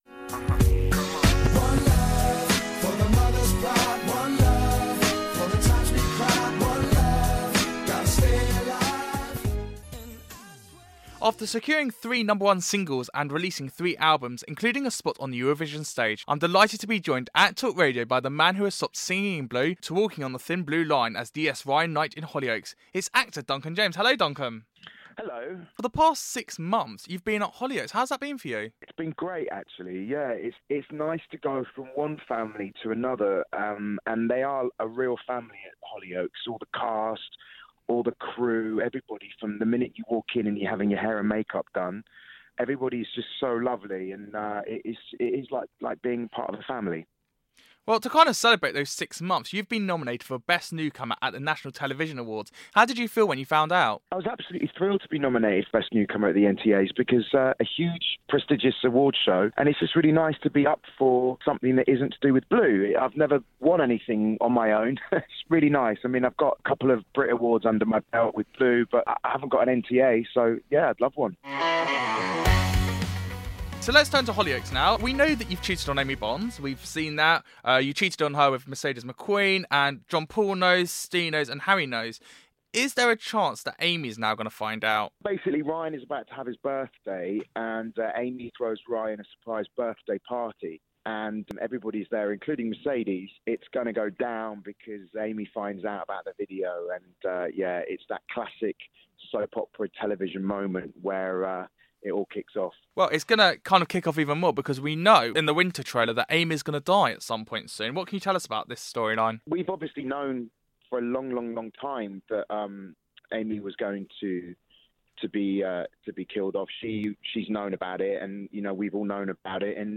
Interview with Duncan James